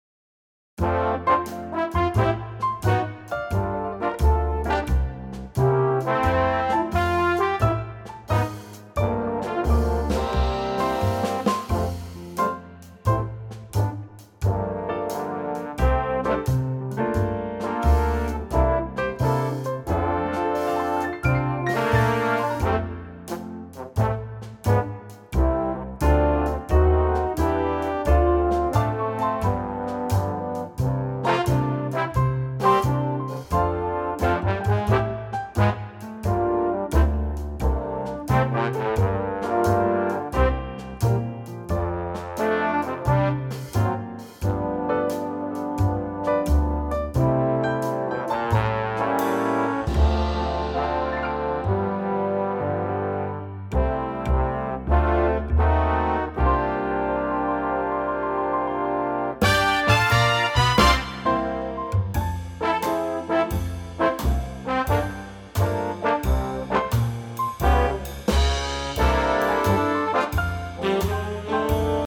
Unique Backing Tracks
Great big band arrangement of this old standard